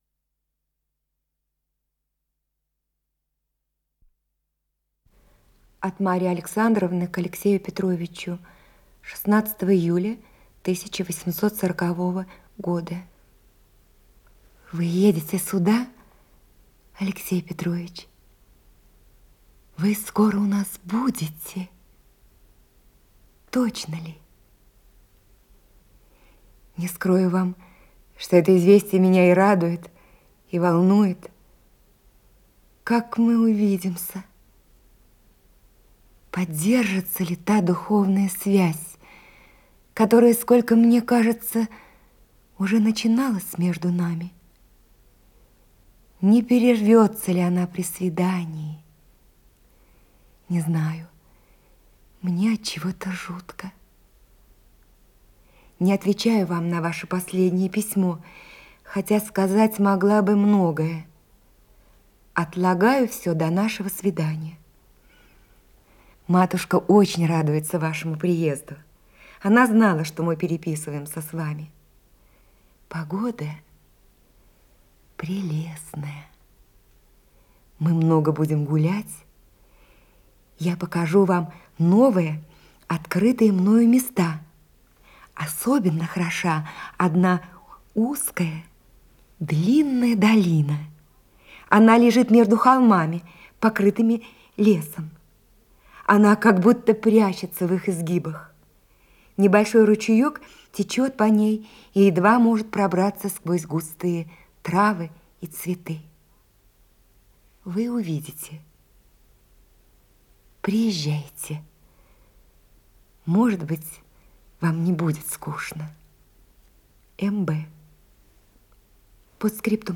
Исполнитель: Лидия Толмачева и Геннадий Бортников - чтение
Повесть